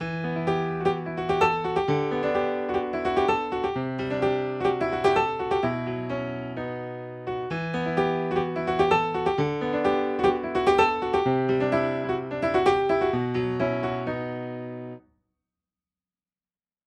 こんな感じのギターフレーズのMIDIデーターをピアノ音源で鳴らしています↓
トランスに合いそうなコード進行で良い感じです。
ただこのままだと揺らぎのあるフレーズになってしまい、トランスっぽいカッチりしたフレーズでは無いのでこれを元に改造していきます。